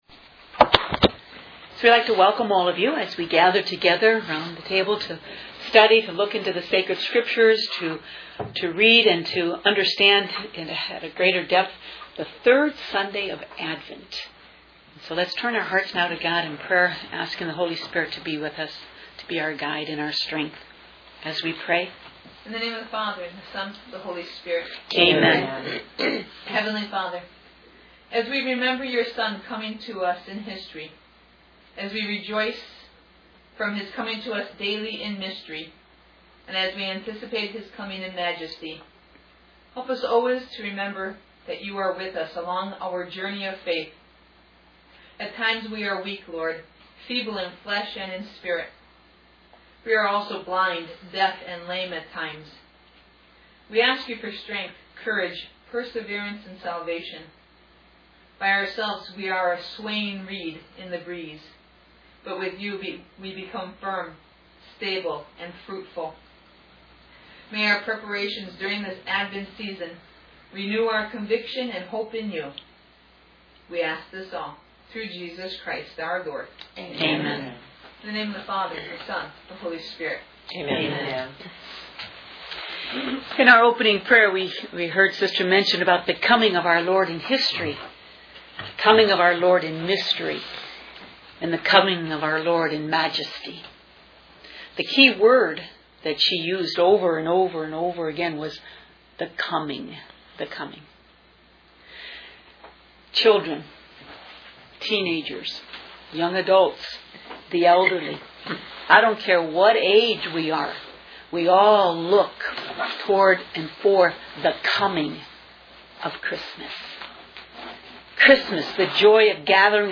This is a Catholic Bible study of the Sunday Mass readings by the Franciscan Sisters of the Sorrowful Mother